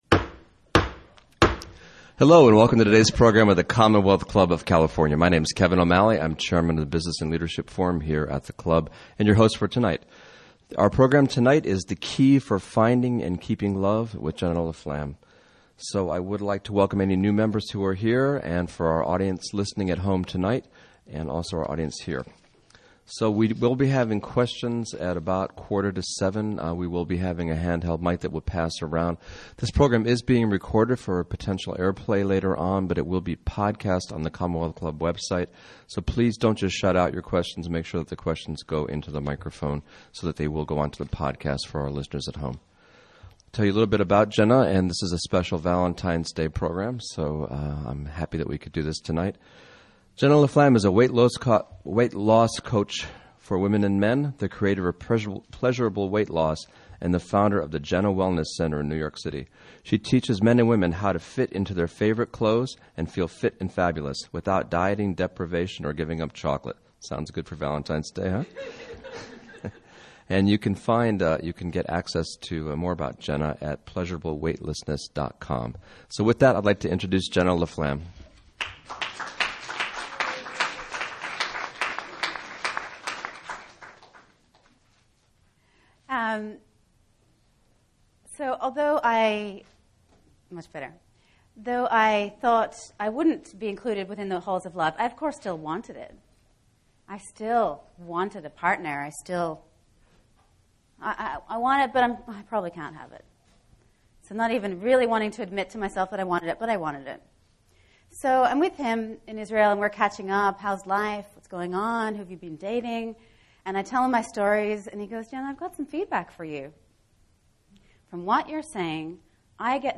Learn how and why the more pleasure you take in your life, the more appealing you will be to your partner (or date) and the more your love will shine and sizzle. Join us for a light-hearted evening with lectures and interactive exercises for singles and couples of all stripes.